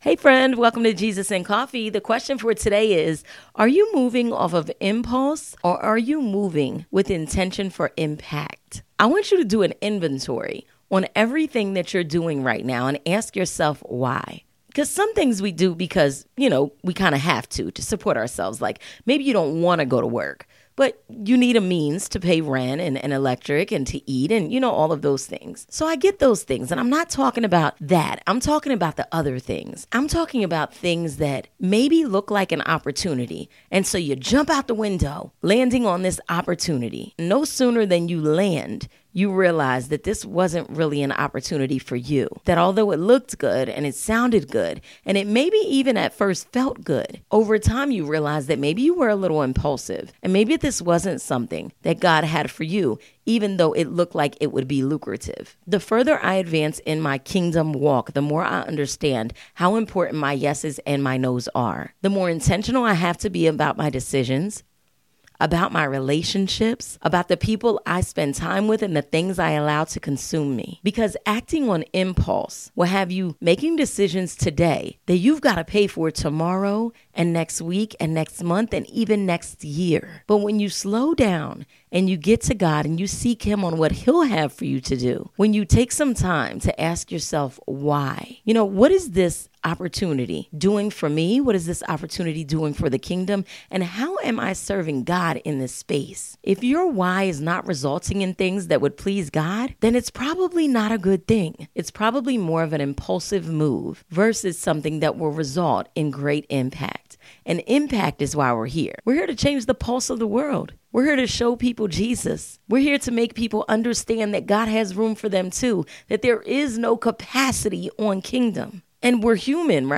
Having a conversation with a friend; designed to help sprinkle some upbeat positivity in the listeners day. There will be storytelling, the occasional guest interview, and biblical reference to help set the tone.